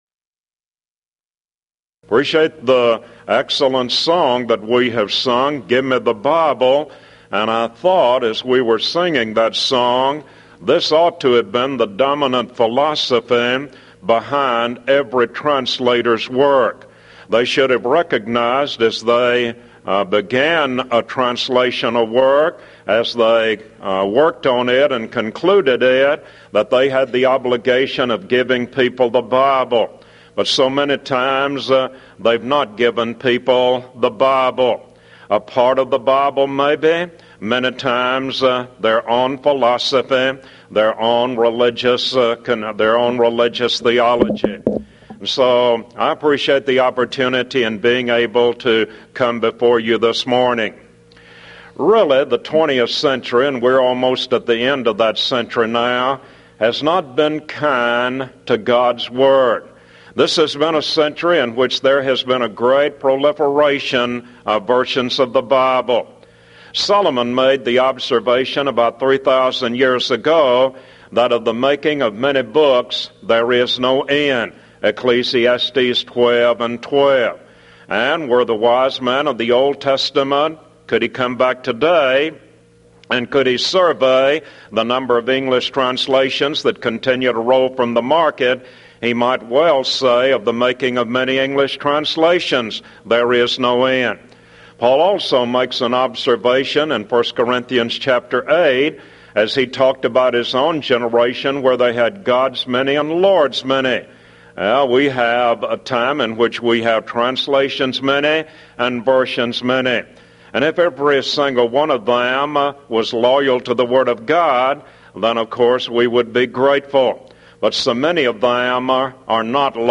Event: 1995 Mid-West Lectures
lecture